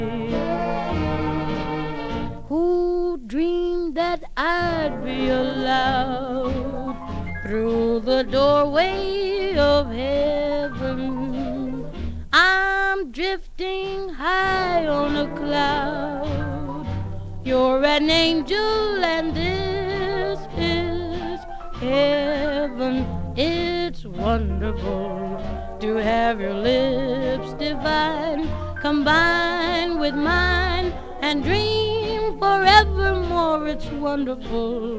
Саксофон и лёгкий свинг
Жанр: Джаз